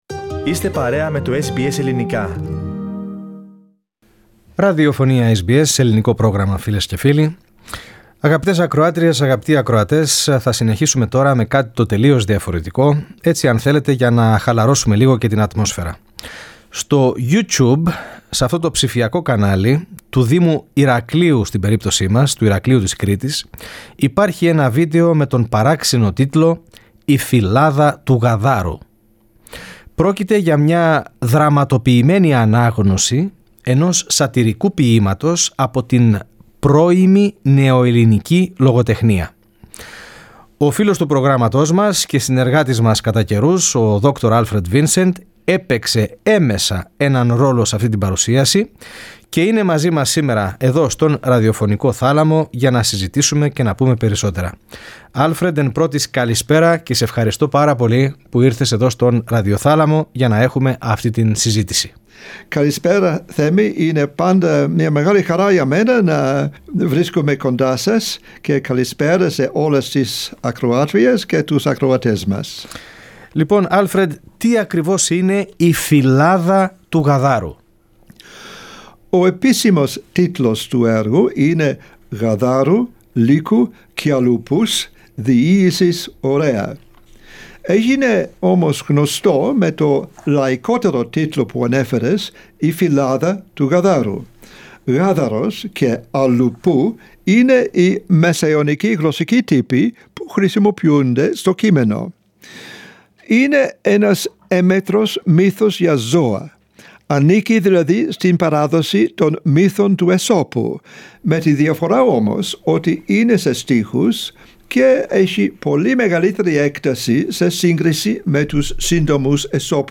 Στην παρουσίασή μας ακούγονται αποσπάσματα από την δραματοποιημένη ανάγνωση αυτού ποιήματος που υπάρχει στο YouTube, στο ψηφιακό κανάλι του Δήμου Ηρακλείου, Κρήτης.
Τον ρόλο του αφηγητή τον μοιράζονται και οι τρεις ηθοποιοί.